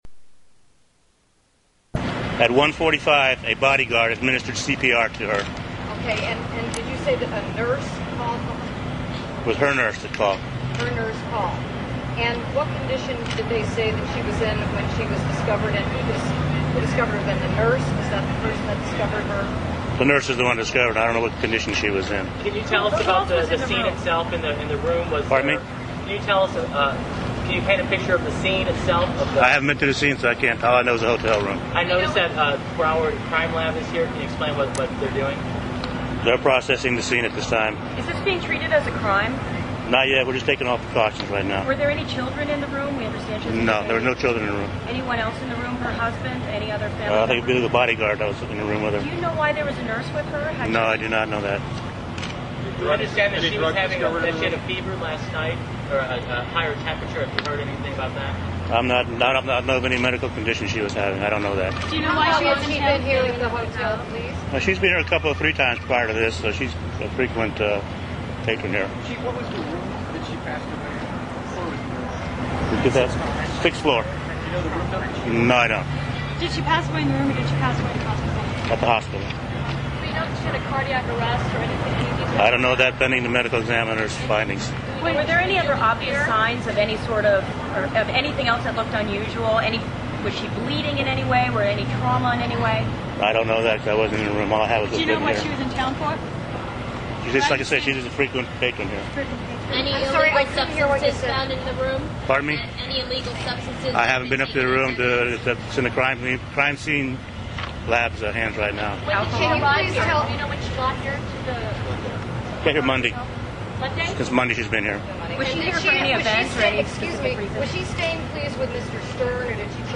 Dead - Police Conference (5 min.)